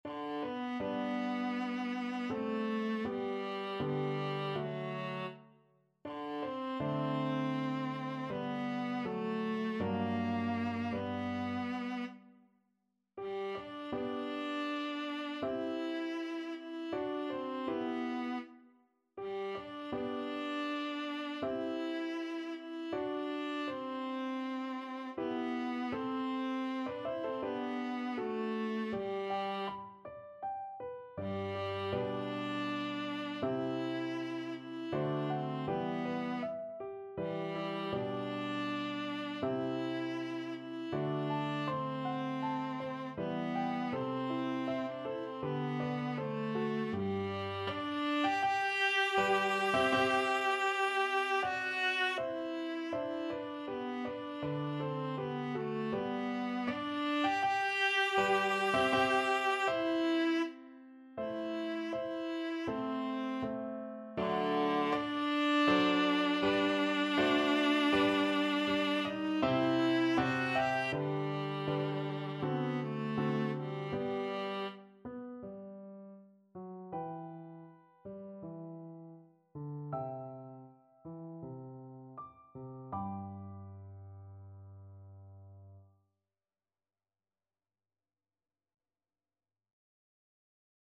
Viola
G major (Sounding Pitch) (View more G major Music for Viola )
4/4 (View more 4/4 Music)
Andante
D4-G5
Classical (View more Classical Viola Music)